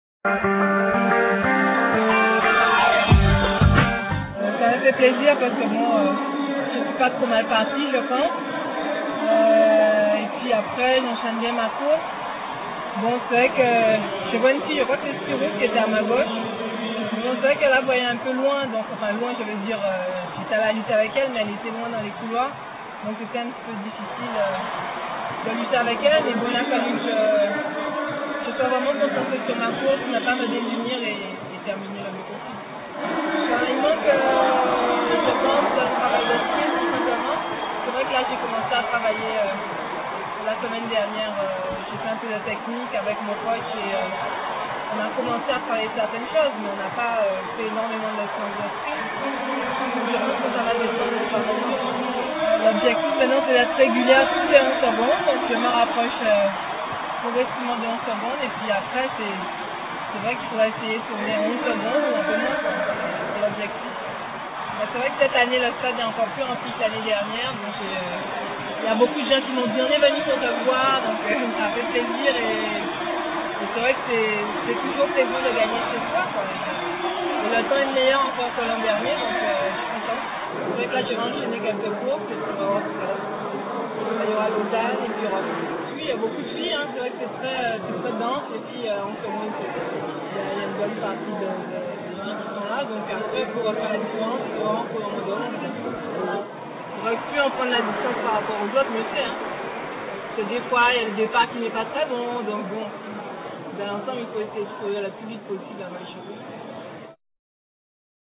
Interviews internationales
itw_christine_arron_bd.rm